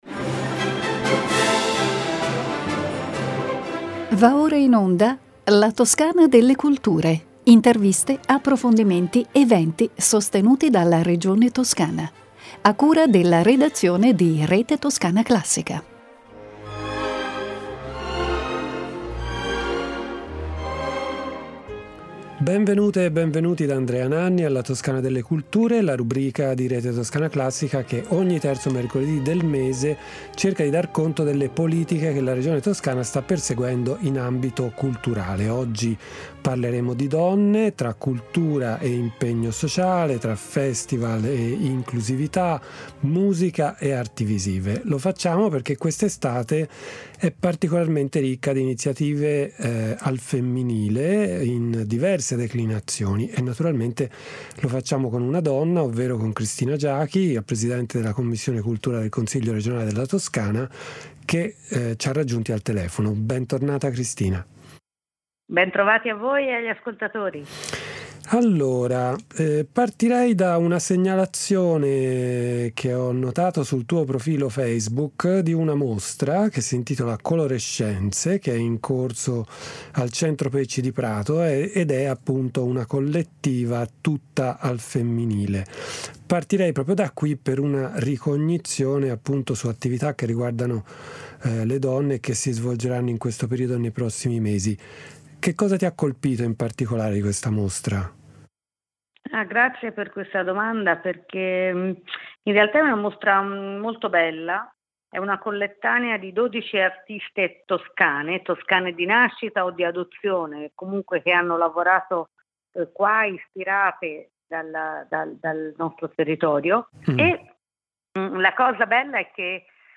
Ne parliamo con Cristina Giachi, Presidente della Quinta commissione del Consiglio regionale della Toscana.